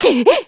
Lucikiel_L-Vox_Damage_kr_02.wav